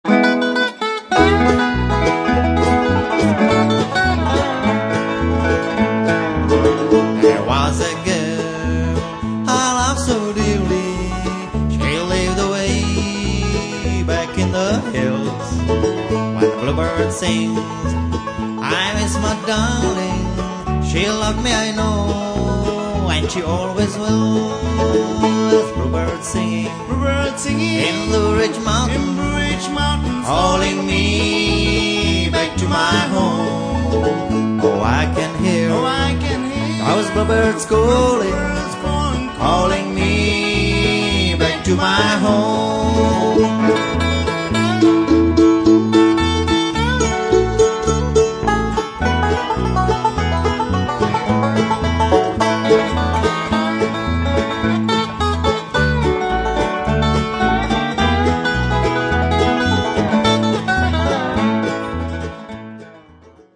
banjo
dobro
mandolin